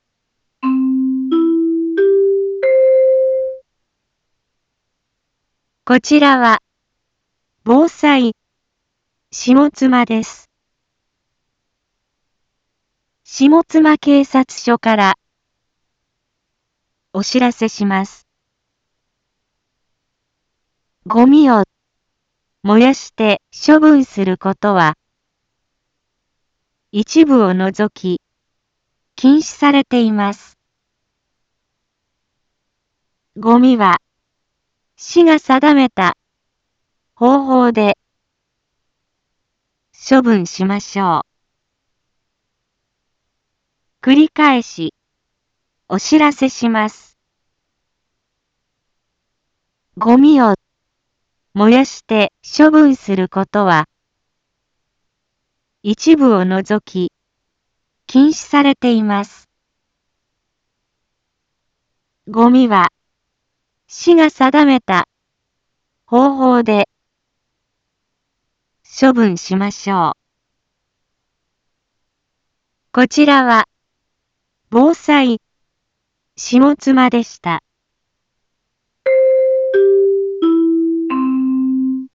一般放送情報
Back Home 一般放送情報 音声放送 再生 一般放送情報 登録日時：2021-10-25 10:01:26 タイトル：ごみの不法焼却の注意喚起について インフォメーション：こちらは、防災下妻です。